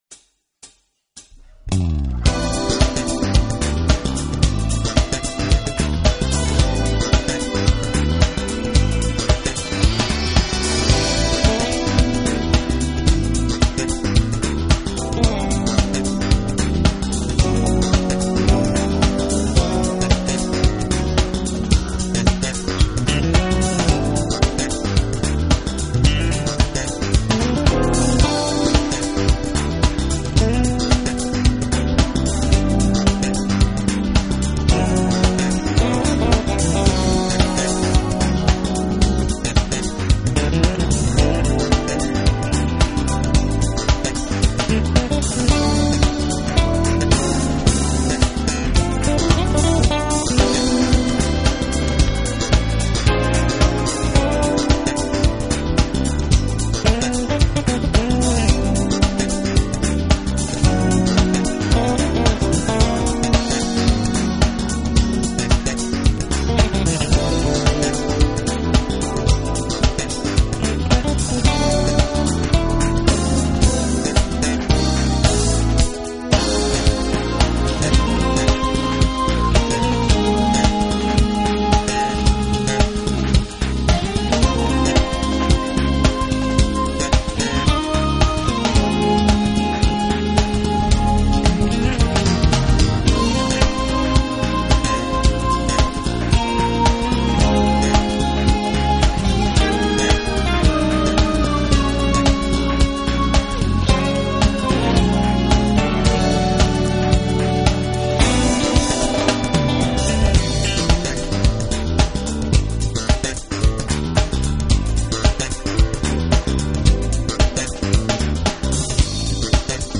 tenor sax